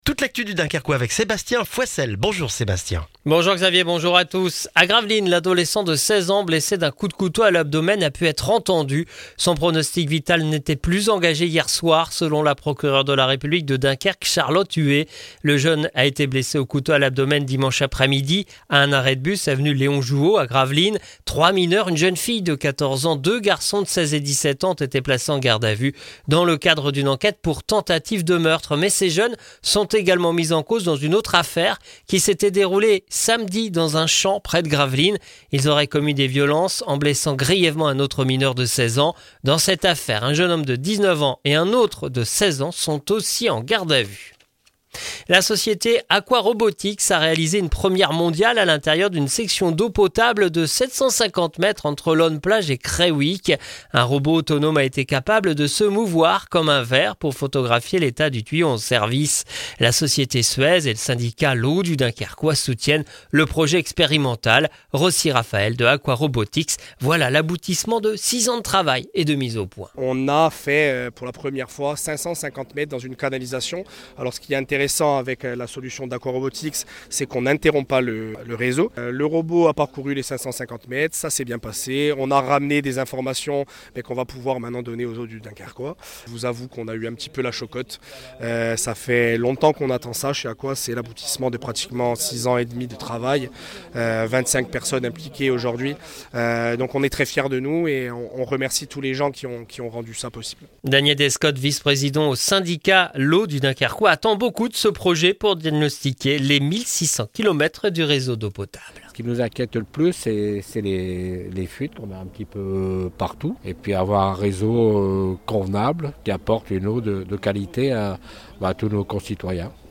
Le journal du mardi 5 novembre dans le Dunkerquois